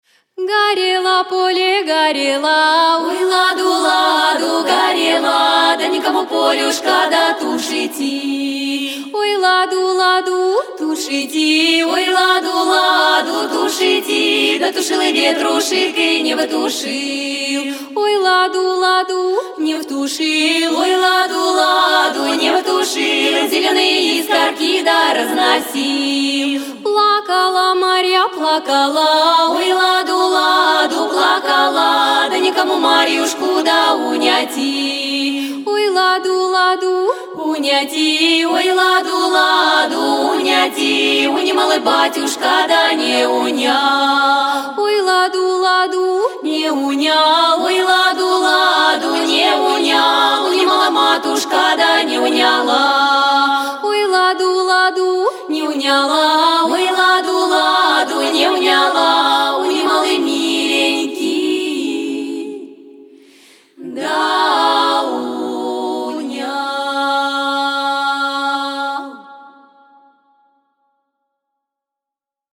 Прослушать оригинал песни: